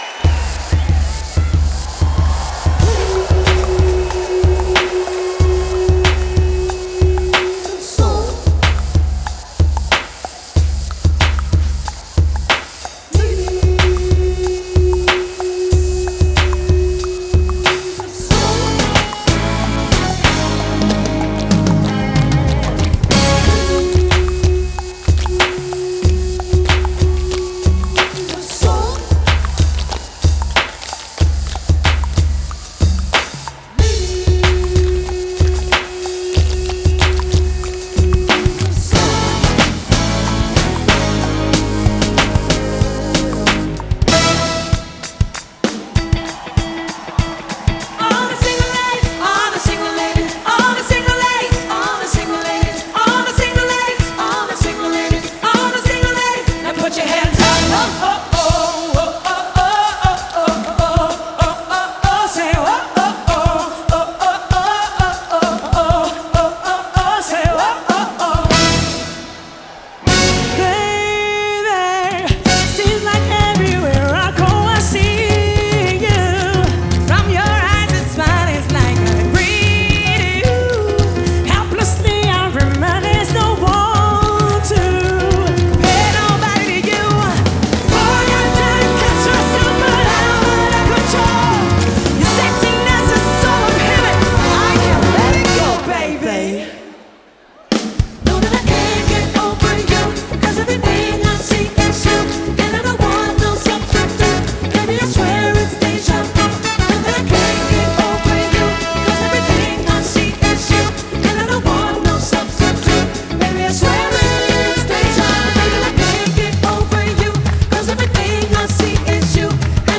Genre: Soul.